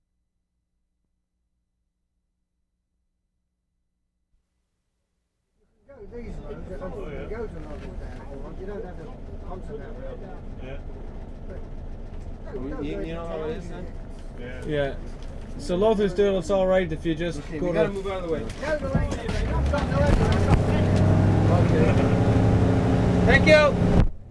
C 7-10. ASKING FOR DIRECTIONS
Directions with engine running.
7-10. Descriptions of directions very complicated; mostly women describing, with nice accents.